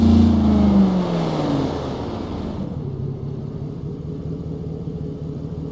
Index of /server/sound/vehicles/lwcars/chev_suburban
slowdown_slow.wav